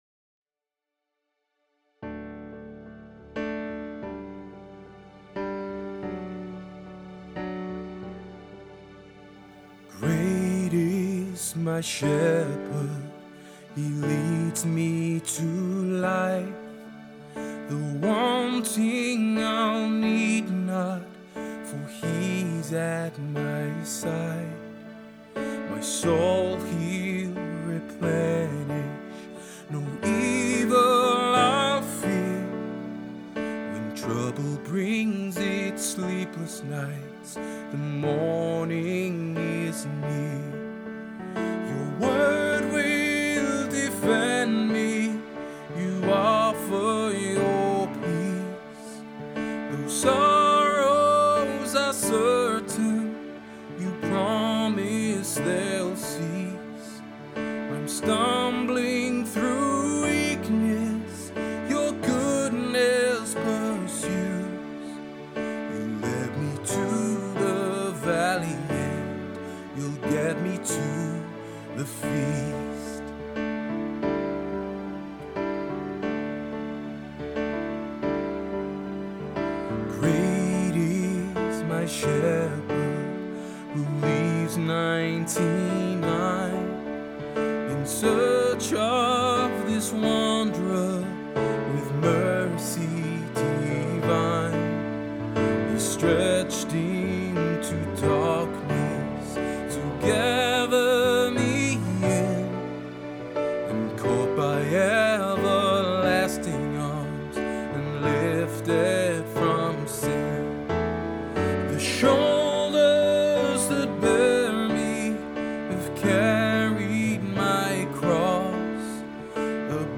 Great-is-my-Shepherd-demo.mp3